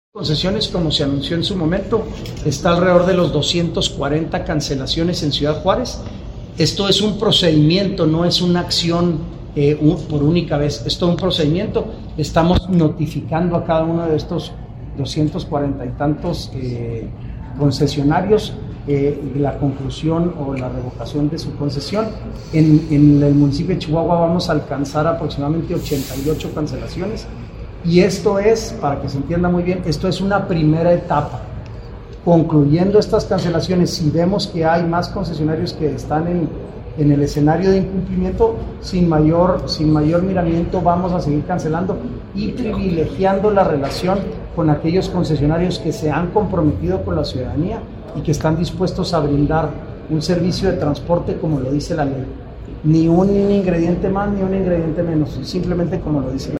AUDIO: SANTIAGO DE LA PEÑA GRAJEDA, DECRETARÍA GENERAL DE GOBIERNO (SGG)